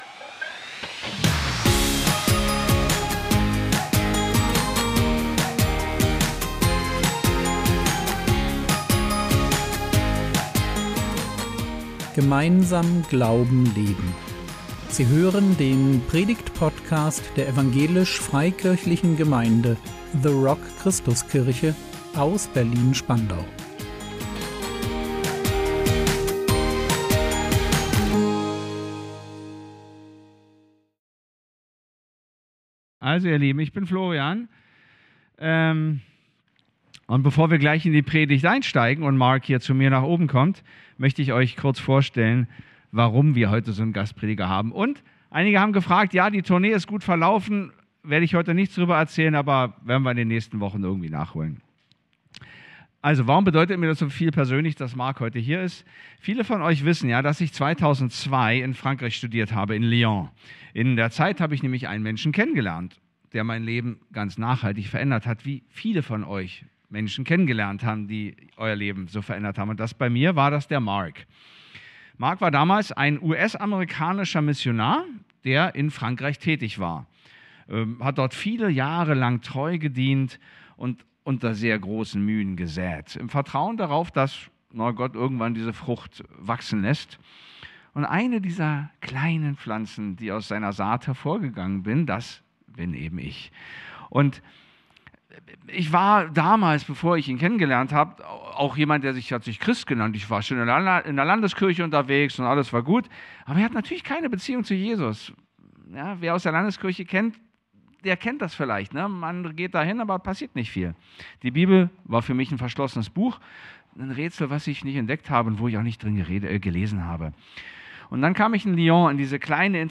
Klein werden vor Gott | 01.02.2026 ~ Predigt Podcast der EFG The Rock Christuskirche Berlin Podcast